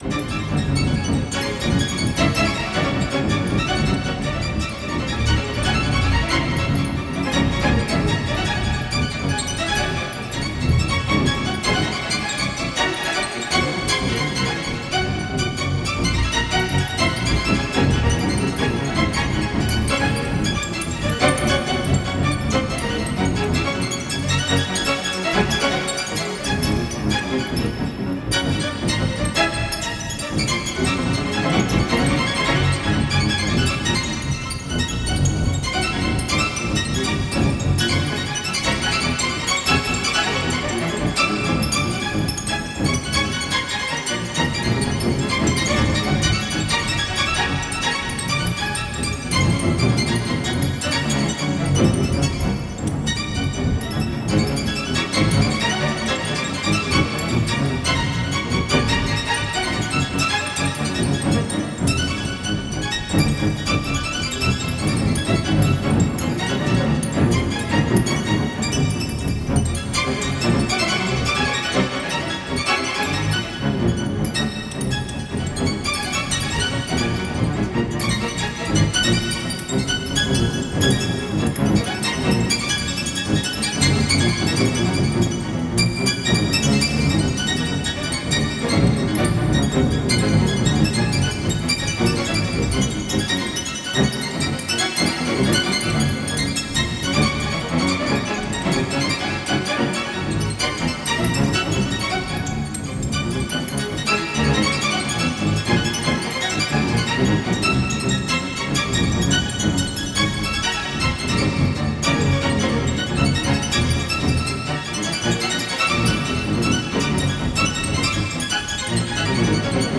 まさに、天使達が奏でる天国のストリングス・オーケストラっていう感じです。
気持ちよくなって、上昇感を持ちながら酩酊し、空気まで良い匂いに染まっていく様な、そんなアルバム。